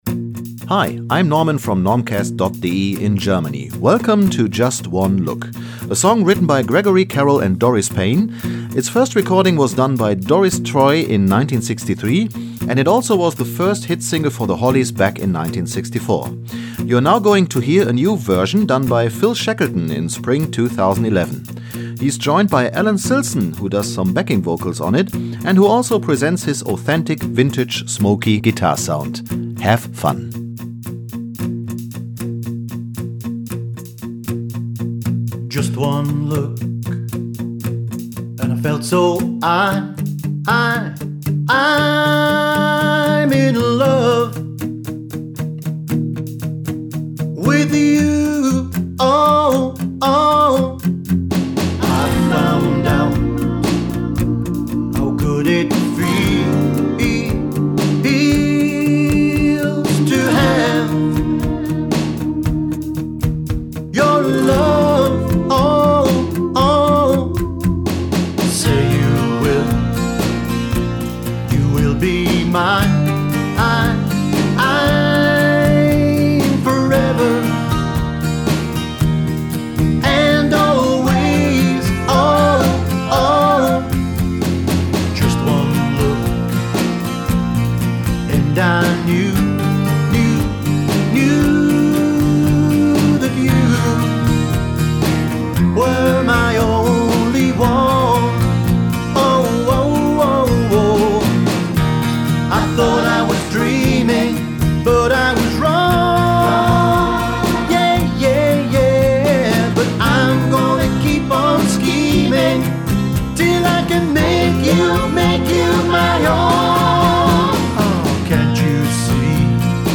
I played bass guitar to fit the new arrangement
and added backing vocs to the coda.